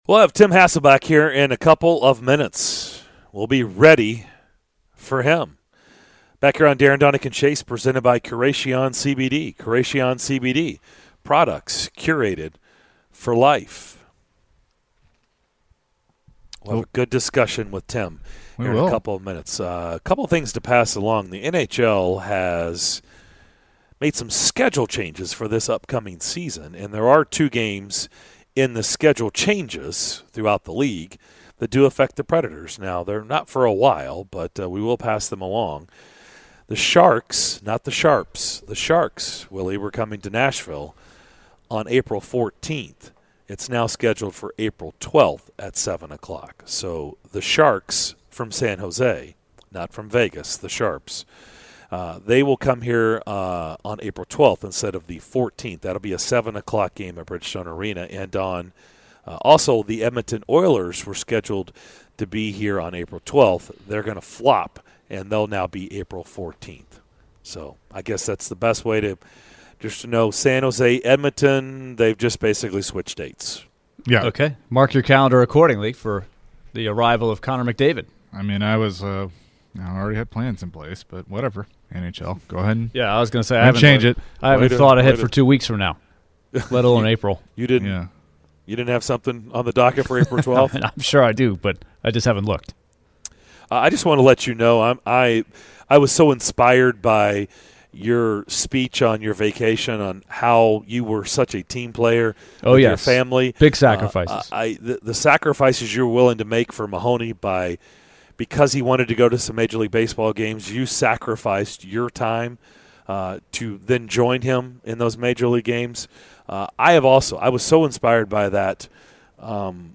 ESPN NFL Analyst Tim Hasselbeck joined the DDC to break down the biggest stories in the NFL going in to week one!